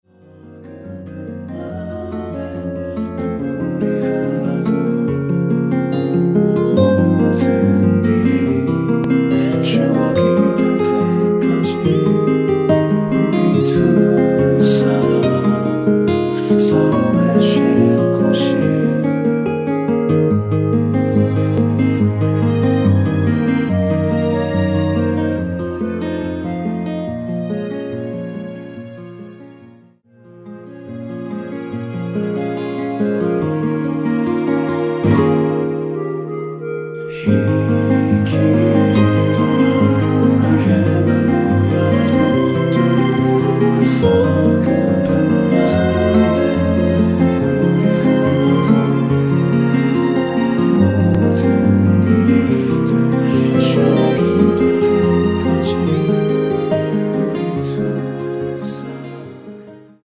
1절후 바로 후렴으로 진행 됩니다.(본문의 가사 참조)
원키에서(-1)내린 2절 삭제한 멜로디와 코러스 포함된 MR입니다.(미리듣기 확인)
Ab
앞부분30초, 뒷부분30초씩 편집해서 올려 드리고 있습니다.
중간에 음이 끈어지고 다시 나오는 이유는